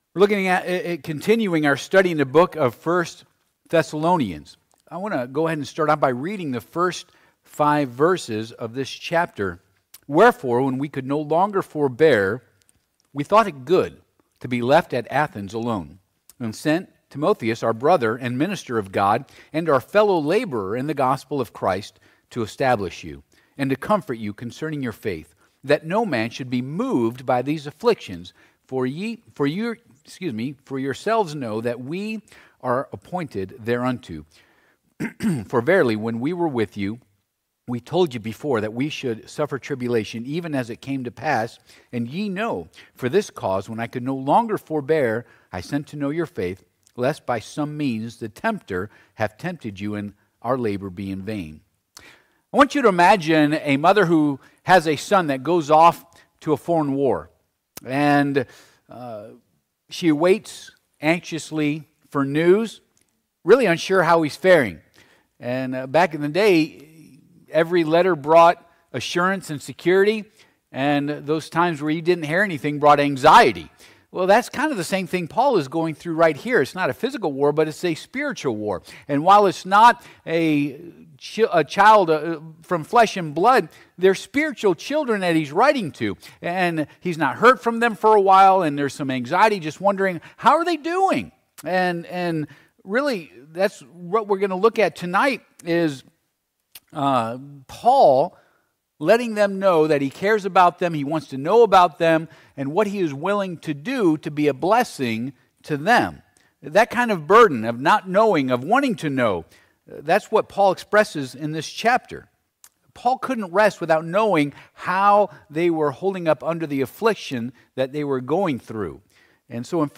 I Thess. 3:1-5 Service Type: Midweek Service « What Must I Do to Be Saved?